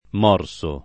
morso [ m 0 r S o ]